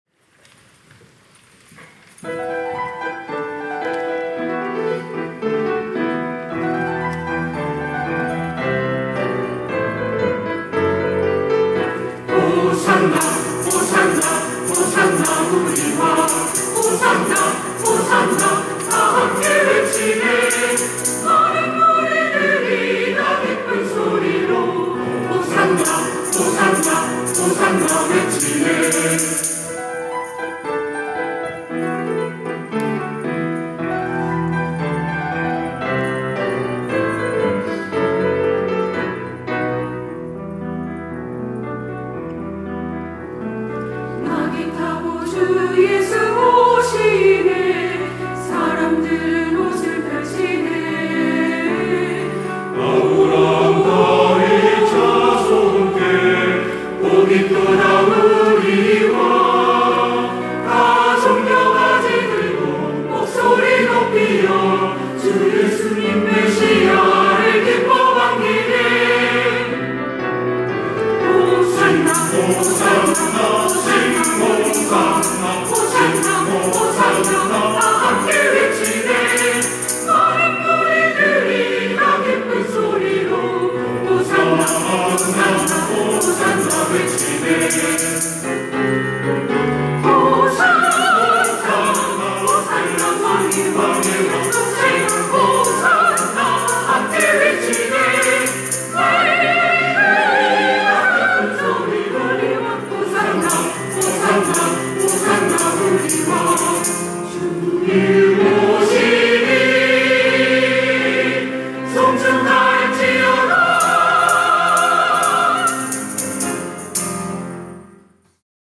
시온(주일1부) - 호산나, 호산나
찬양대 시온